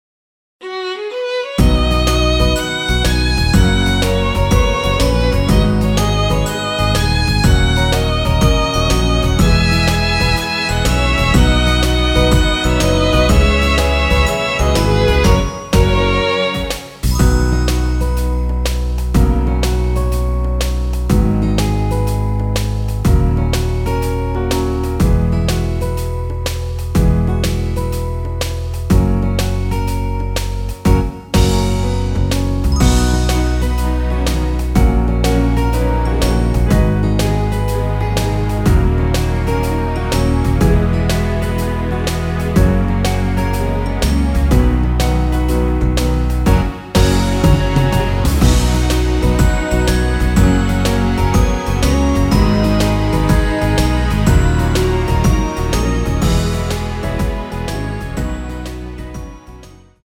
남자키 MR 입니다.
앞부분30초, 뒷부분30초씩 편집해서 올려 드리고 있습니다.
중간에 음이 끈어지고 다시 나오는 이유는